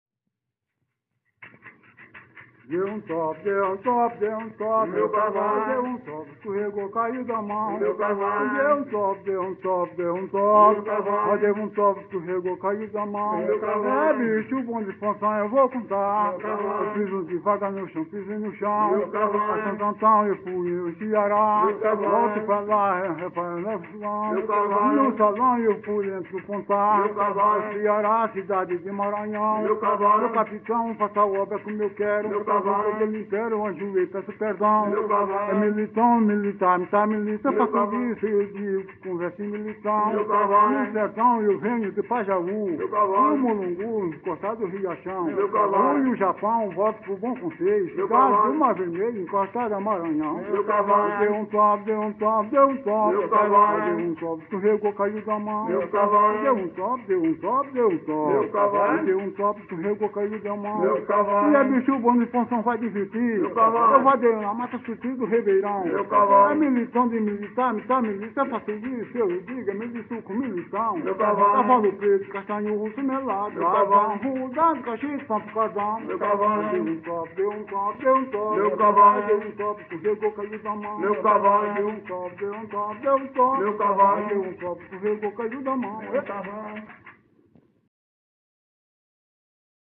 Coco -“”Meu Cavalo Marinho”” - Acervos - Centro Cultural São Paulo